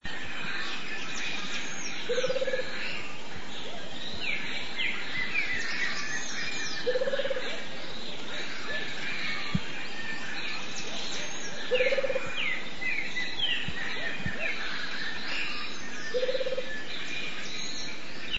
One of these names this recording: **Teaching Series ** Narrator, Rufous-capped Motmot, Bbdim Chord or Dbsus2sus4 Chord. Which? Rufous-capped Motmot